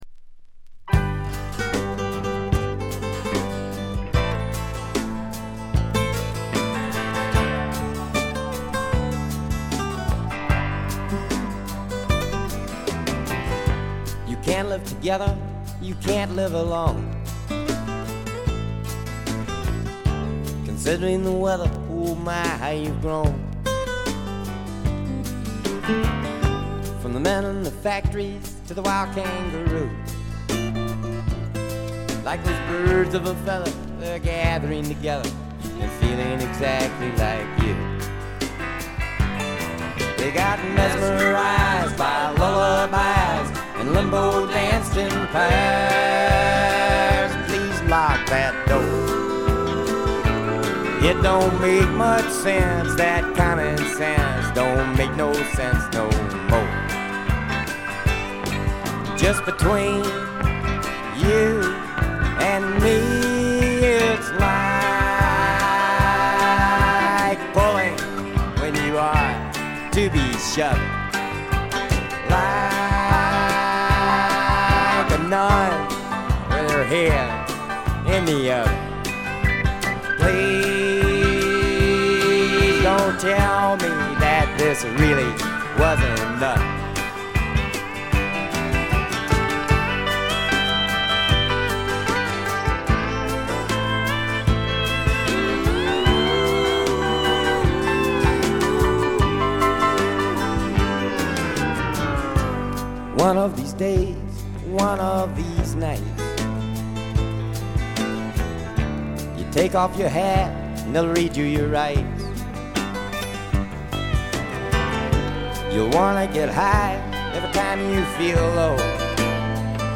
部分試聴ですが軽微なチリプチ少々程度。
試聴曲は現品からの取り込み音源です。
vocals, acoustic guitar